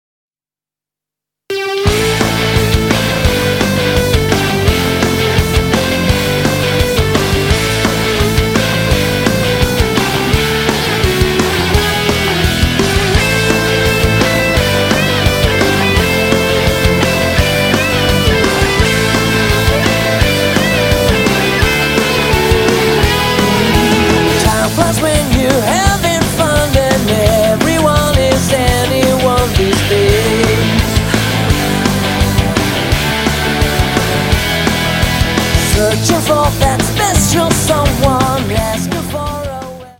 Genre Melodic Rock
Vocals
Guitar
Bass
Drums
Keyboard
If you are a fan of melodic rock with catchy melodies: buy!